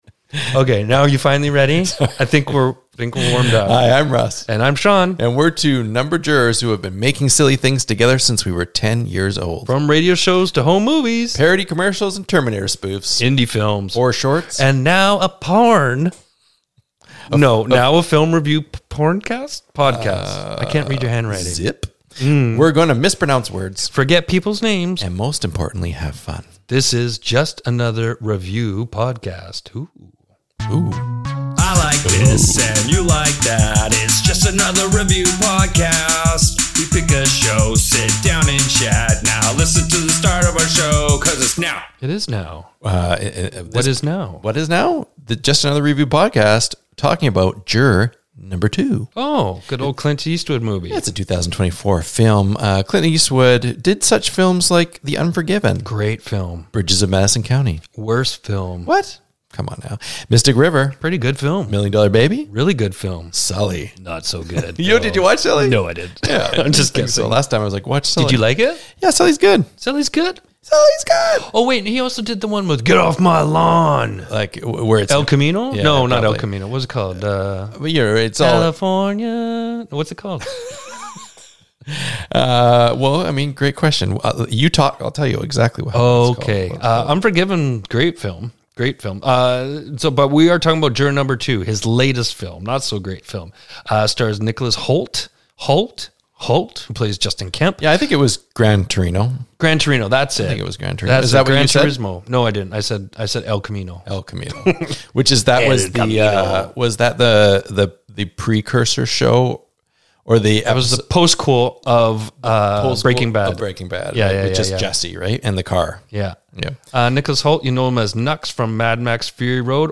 The 2 Jurors mispronounce words, forget actors names, and most importantly have fun.